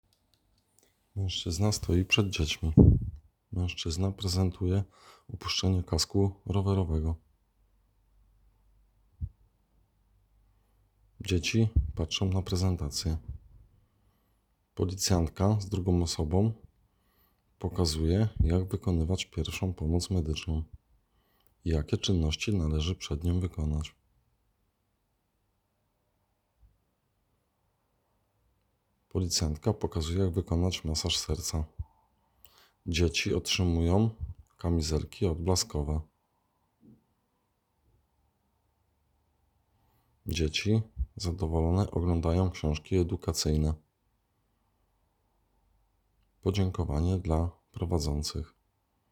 Nagranie audio Bezpieczne_dzieciaki-audiodeskrypcja.m4a